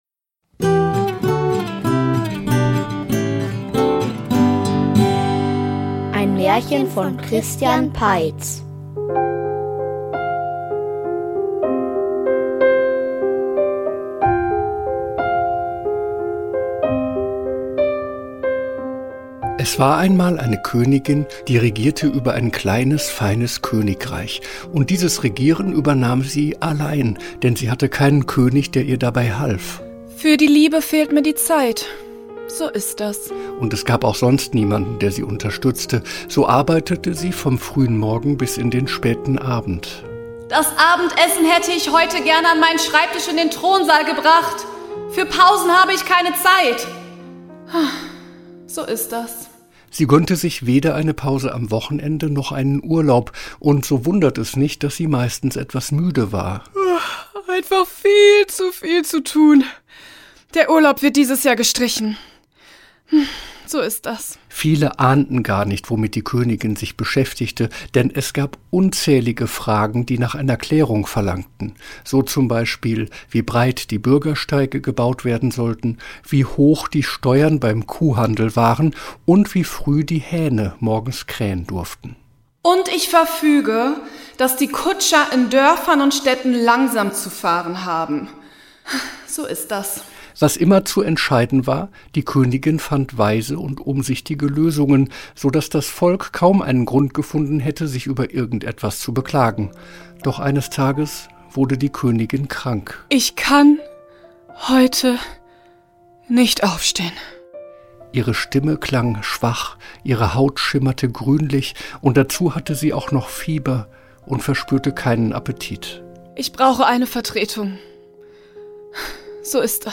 Der General --- Märchenhörspiel #54 ~ Märchen-Hörspiele Podcast